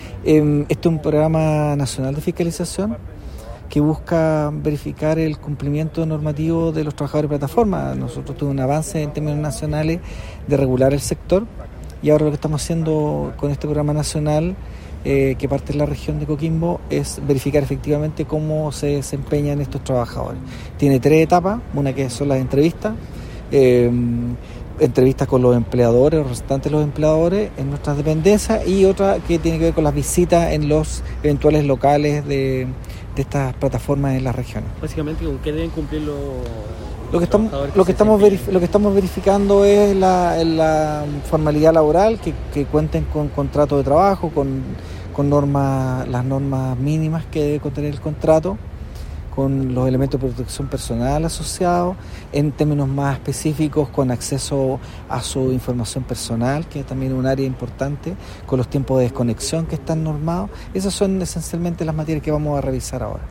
En este sentido el director (s) Mauricio Mundaca, explicó que